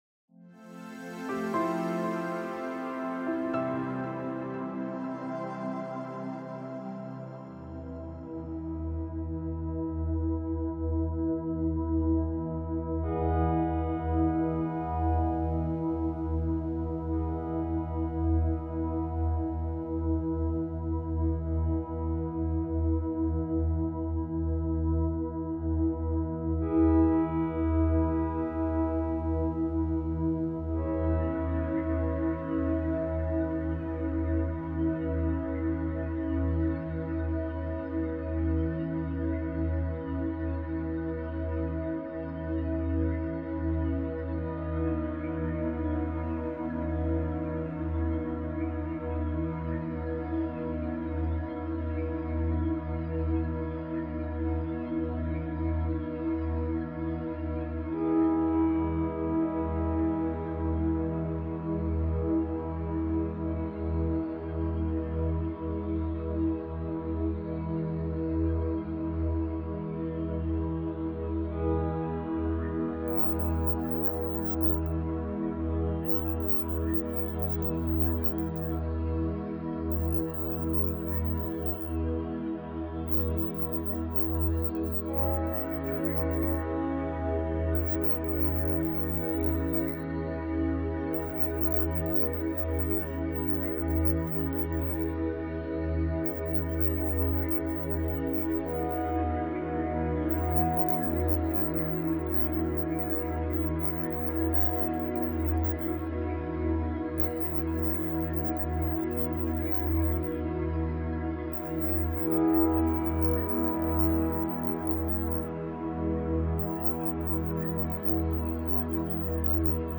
Über diese Folge In dieser Folge des Silent Subliminals Podcast lernst du, wie du negative Glaubenssätze auflösen kannst. Die beruhigende 432 Hz Musik begleitet kraftvolle Silent Subliminals, die dein Unterbewusstsein erreichen und dir helfen, deine tief verwurzelten Glaubenssätze zu transformieren....